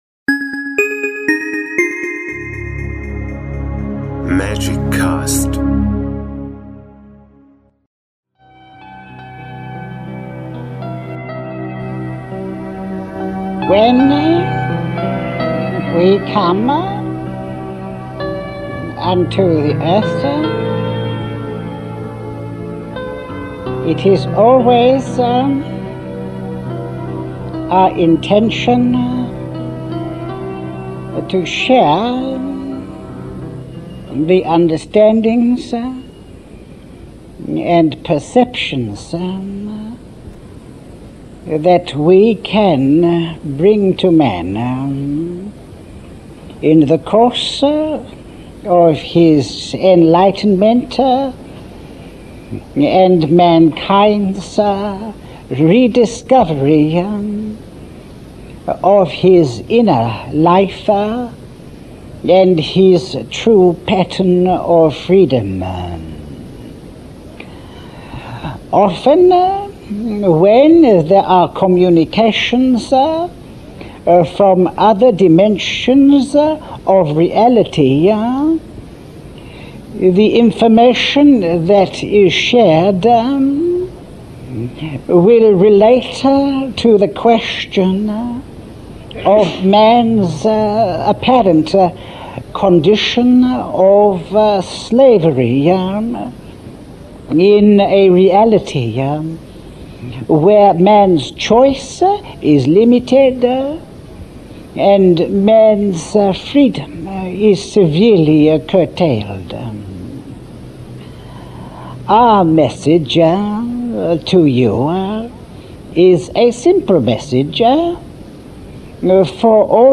Englischer Originalton